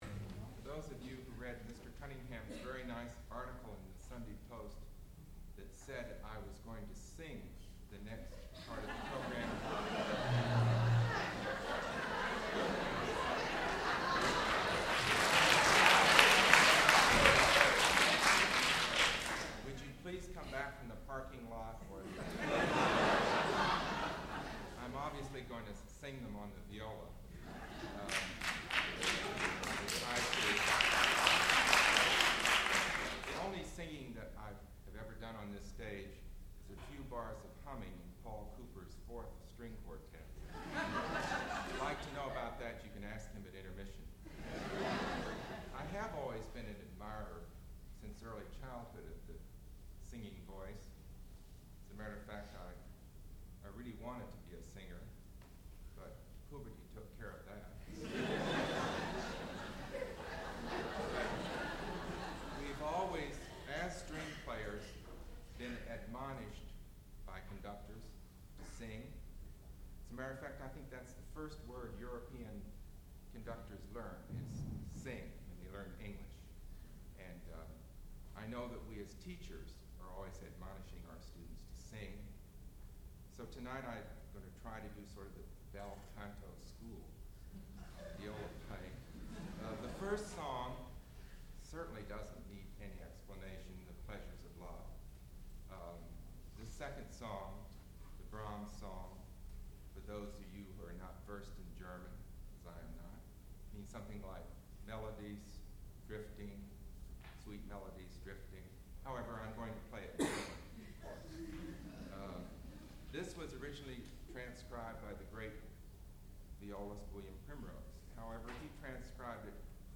sound recording-musical
classical music
viola